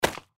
Index of /server/sound/npc/gecko/foot
fs_gecko_r02.mp3